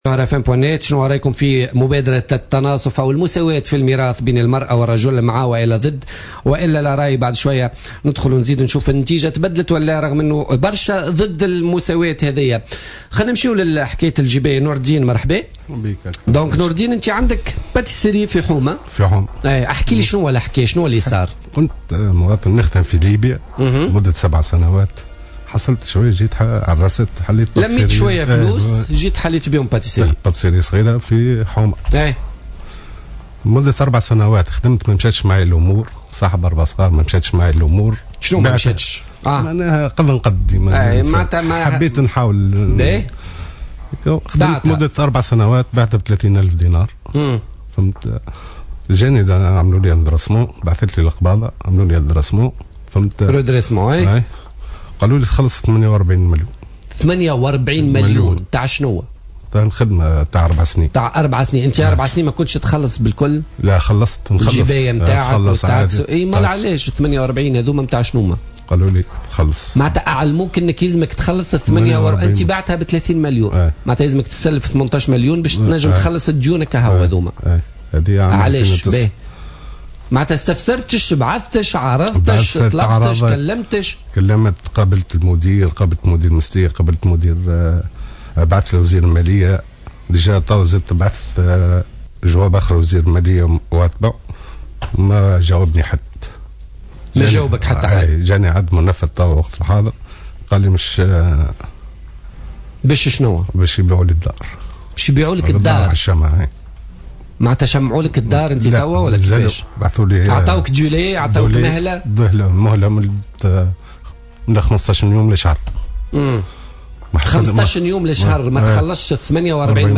طلب مواطن أصيل مدينة قصر هلال من ولاية المنستير اليوم الخميس التدخل في برنامج بوليتيكا لإطلاق نداء استغاثة للسلطات مرجع النظر في قضية تغريمه ب48 ألف دينار من قبل القباضة المالية على اعتباره كان يملك محلا لصنع وبيع المرطبات بأحد الأحياء الشعبية.